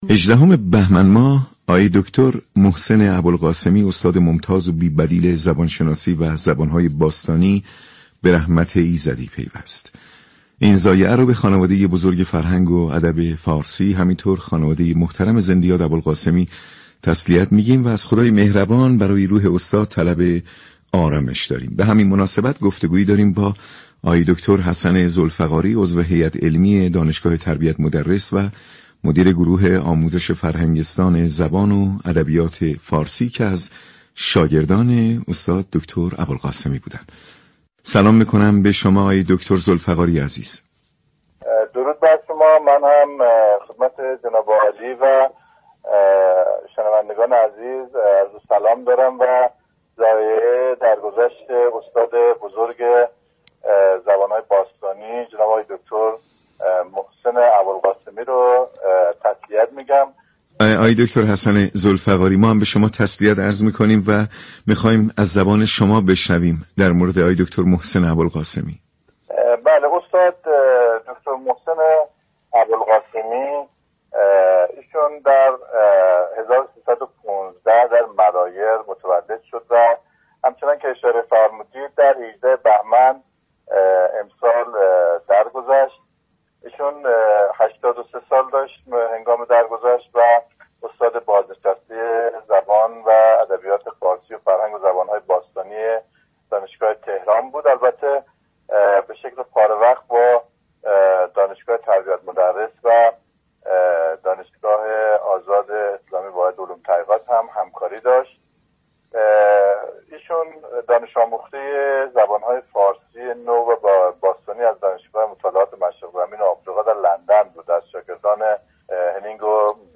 گفت و گوی رادیویی